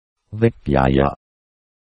I made the soundfiles with the text-to-speech program at: